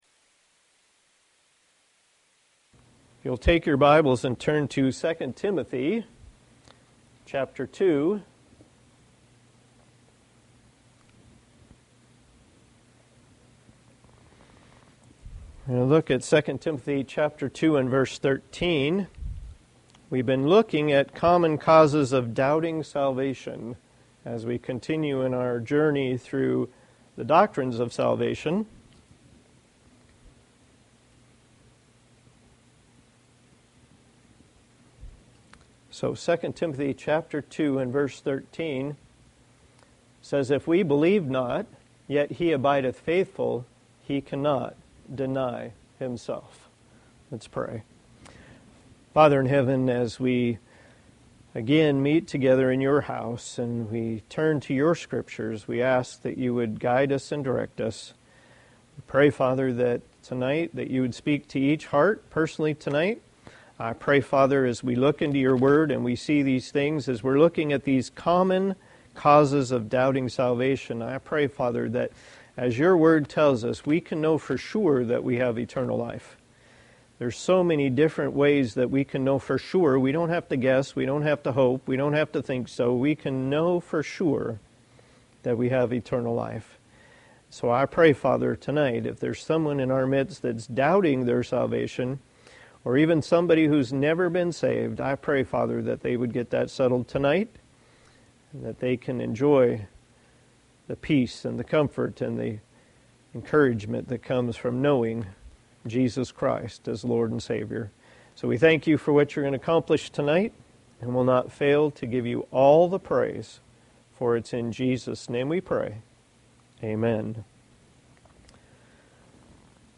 Service Type: Thursday Evening